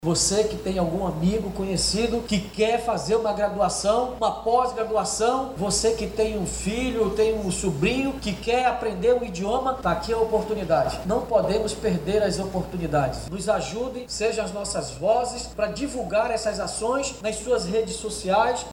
O chefe do Executivo Municipal aproveitou a ocasião para pedir que as pessoas divulguem os programas, nas redes sociais, para que alcancem o maior número de cidadãos.